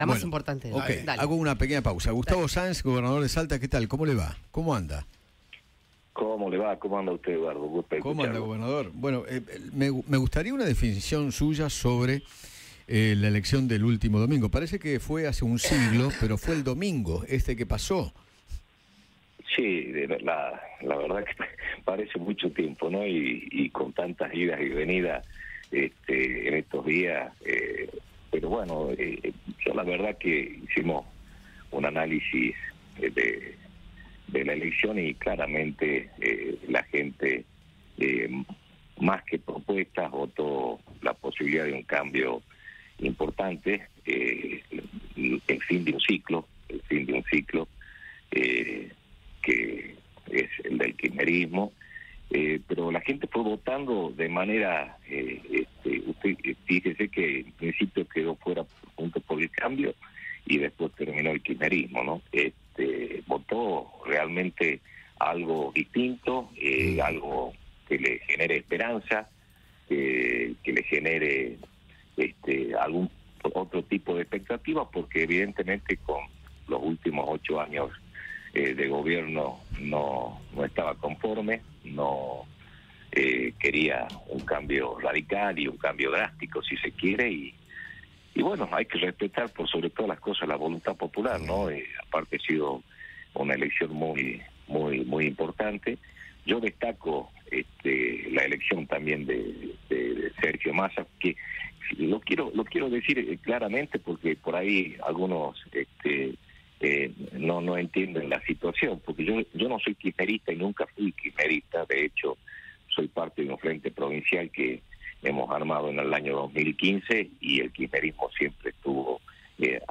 Gustavo Sáenz, gobernador de Salta, dialogó con Eduardo Feinmann sobre la victoria de Javier Milei en el balotaje presidencial.